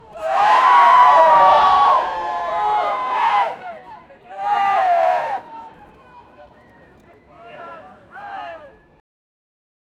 Gritty texture, loud projection, combat scenario, sharp and abrupt, angry denial. 0:05 There is fire all around. People are running away shouting sound 0:10
there-is-fire-all-around-lkrifnye.wav